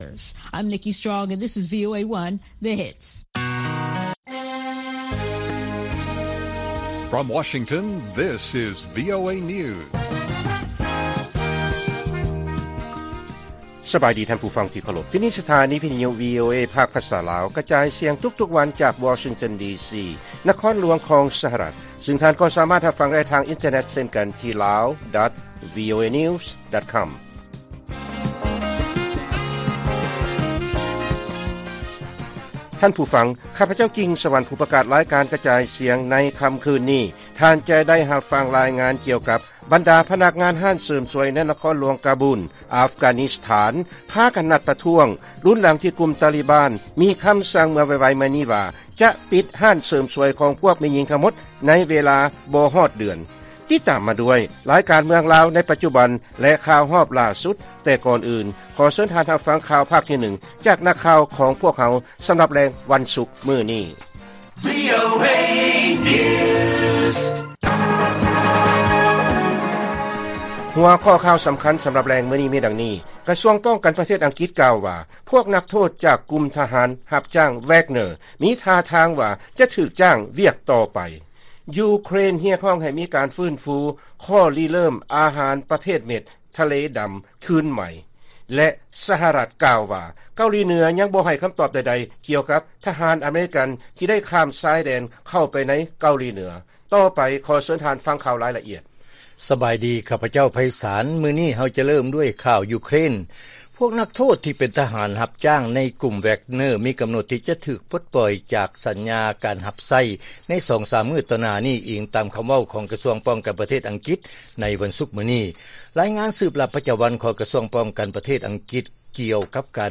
ວີໂອເອພາກພາສາລາວ ກະຈາຍສຽງທຸກໆວັນ, ຫົວຂໍ້ຂ່າວສໍາຄັນໃນມື້ນີ້ມິ: 1. ກະຊວງປ້ອງກັນປະເທດອັງກິດກ່າວວ່າ ທະຫານຮັບຈ້າງແວັກເນີ ຍັງຈະຖືກຈ້າງວຽກຕໍ່ໄປ, 2. ຢູເຄຣນຮຽກຮ້ອງໃຫ້ມີການຟື້ນຟູ ຂໍ້ລິເລີ້ມອາຫານປະເພດເມັດທະເລດຳ ຄືນໃໝ່, ແລະ 3. ສະຫະລັດກ່າວວ່າ ເກົາຫຼີເໜືອຍັງບໍ່ໃຫ້ຄຳຕອບໃດໆກ່ຽວກັບທະຫານອາເມຣິກັນ.